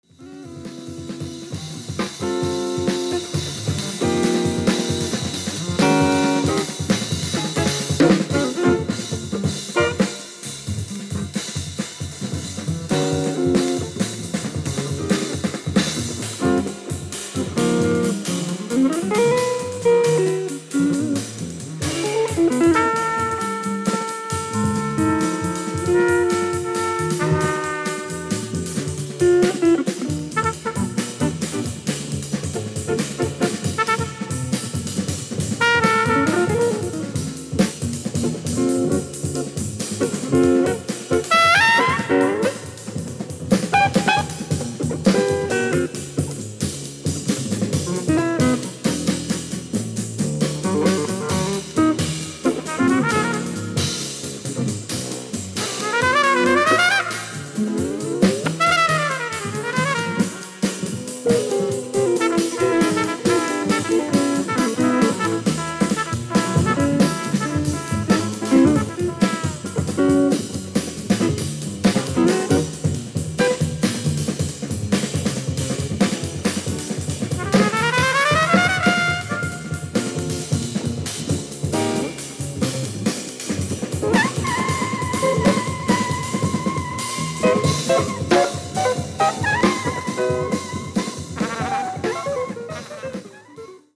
Bass
Drums
Guitar
Trumpet